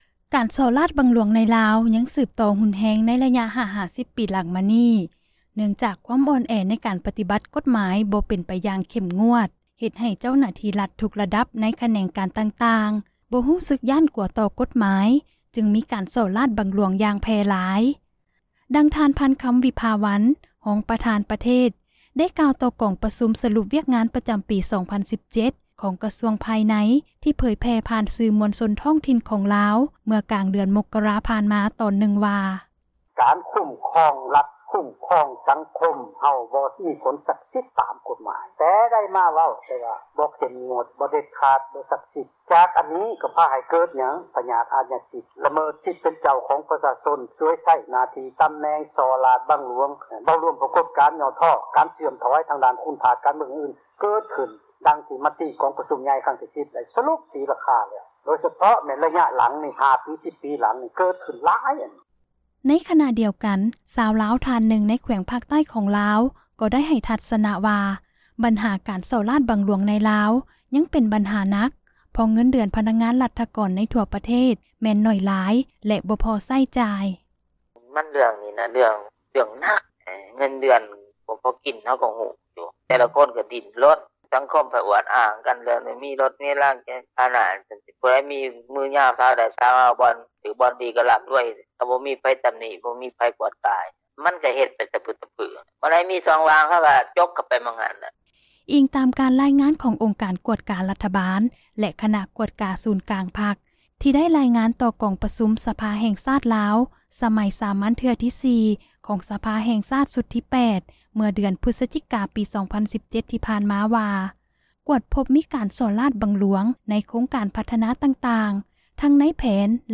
ການສໍ້ຣາສບັງຫຼວງໃນລາວ ຍັງສືບຕໍ່ຮຸນແຮງ ໃນລະຍະ 5 ຫາ 10 ປີຫຼັງມານີ້ ເນື່ອງຈາກຄວາມອ່ອນແອ ໃນການປະຕິບັດ ກົດໝາຍ ບໍ່ເປັນໄປຢ່າງເຂັ້ມງວດ ເຮັດໃຫ້ເຈົ້າໜ້າທີ່ຣັຖ ທຸກຣະດັບ ໃນຂແນງການຕ່າງໆ ບໍ່ຢ້ານກົວຕໍ່ກົດໝາຍ ຈຶ່ງມີການ ສໍ້ຣາສບັງຫຼວງ ຢ່າງ ແຜ່ຫຼາຍ, ດັ່ງທ່ານ ພັນຄຳ ວິພາວັນ, ຮອງປະທານປະເທສ ໄດ້ກ່າວຕໍ່ກອງປະຊຸມ ສລຸບວຽກງານ ປະຈຳປີ 2017 ຂອງກະຊວງພາຍໃນ ທີ່ເຜີຍແຜ່ ຜ່ານສື່ມວນຊົນທ້ອງຖິ່ນ ຂອງລາວ ເມື່ອກາງເດືອນມົກຣາ ຜ່ານມາ ໃນຕອນນຶ່ງວ່າ:
ໃນຂະນະດຽວກັນ, ຊາວລາວທ່ານນຶ່ງ ໃນແຂວງພາກໃຕ້ຂອງລາວ ກໍໄດ້ໃຫ້ທັສນະວ່າ ບັນຫາການສໍ້ຣາສບັງຫຼວງ ໃນລາວ ຍັງເປັນບັນຫາ ໜັກເພາະເງິນເດືອນ ພະນັກງານຣັຖກອນ ໃນທົ່ວປະເທດແມ່ນໜ້ອຍຫຼາຍ ແລະບໍ່ພໍໃຊ້ຈ່າຍ: